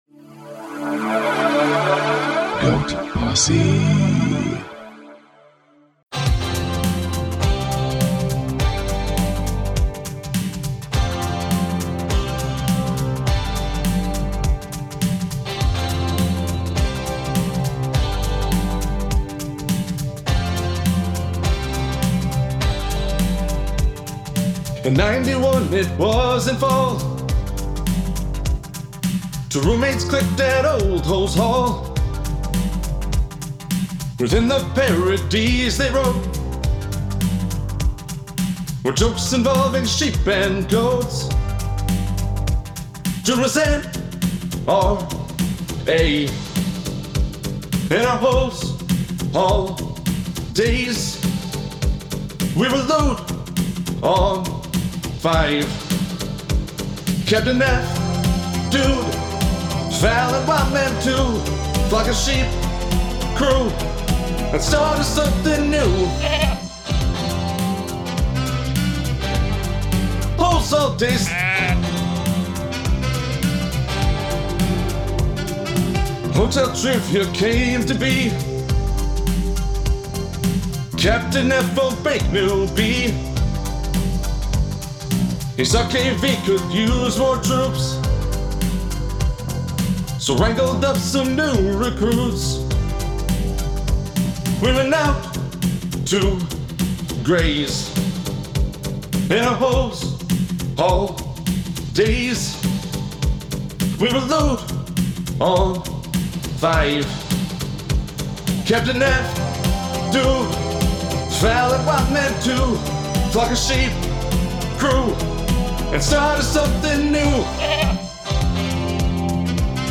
Vocals